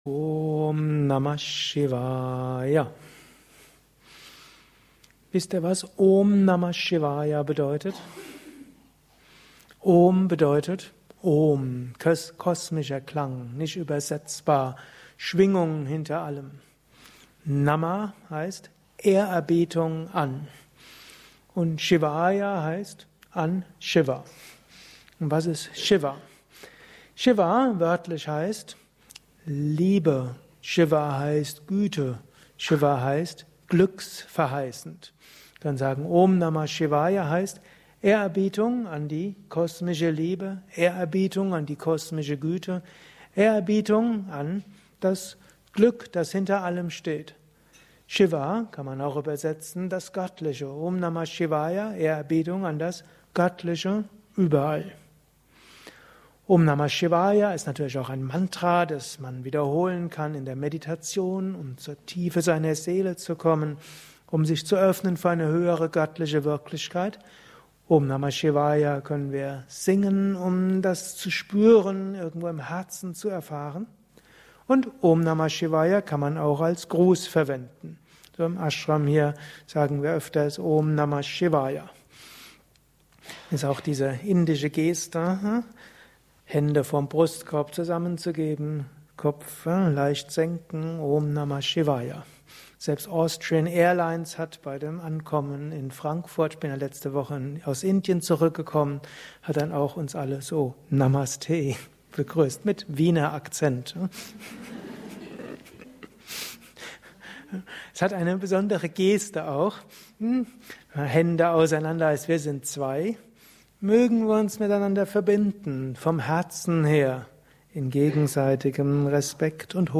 Gelesen im Anschluss nach einer Meditation im Haus Yoga Vidya Bad Meinberg.
Lausche einem Vortrag über die Bedeutung von Om Namah Shivaya